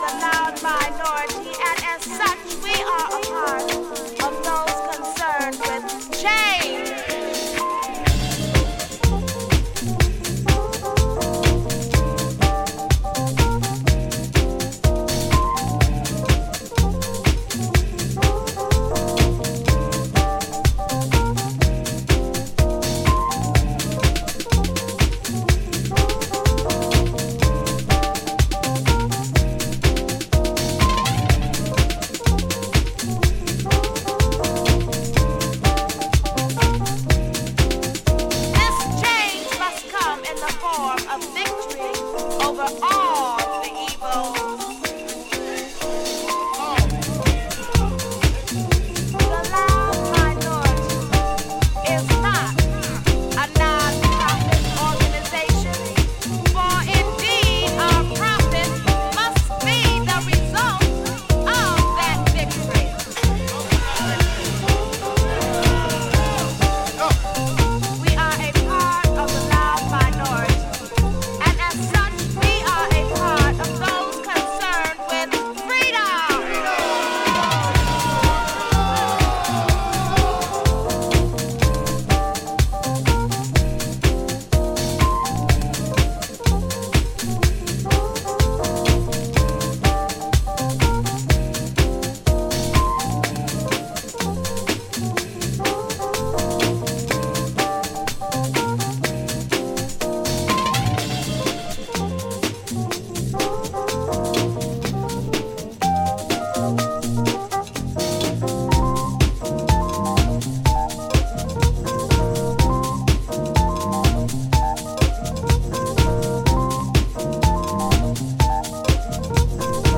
ジャジーなオルガンやガヤつくサンプルが絡む